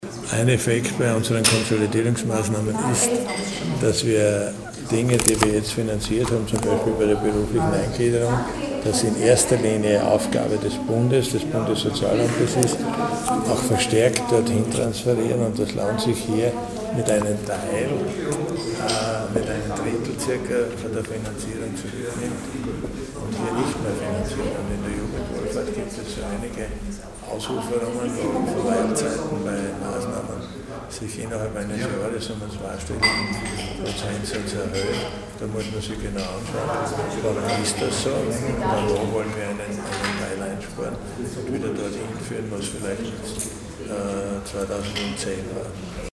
O-Ton: Budgetpräsentation Edlinger-Ploder und Schrittwieser
Oktober 2012).-  Heute (11.10.2012) präsentierten die beiden Landesräte Kristina Edlinger-Ploder und Siegfried Schrittwieser im Medienzentrum Steiermark ihre Ressortdoppelbudgets für die Jahre 2013 und 2014.
Landesrat Siegfried Schrittwieser: